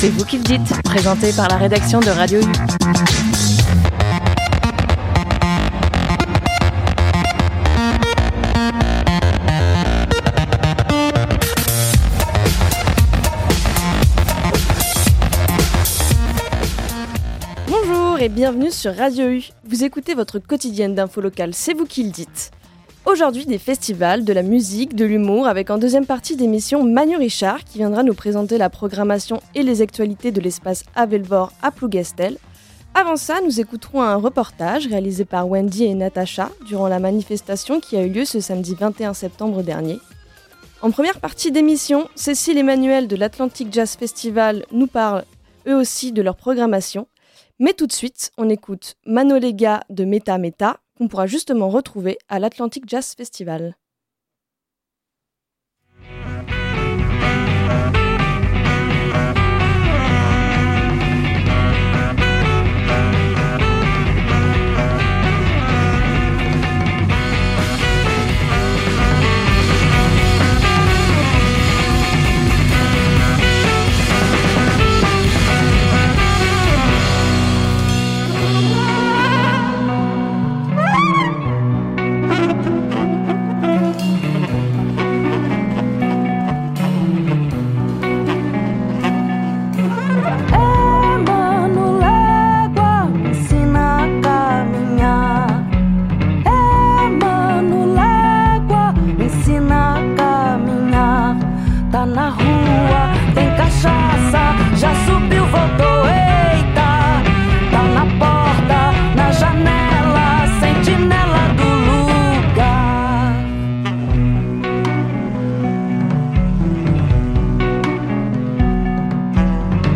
Nous descendrons ensuite dans la rue pour suivre la manifestation d’opposition à la nomination du nouveau premier ministre Michel Barnier.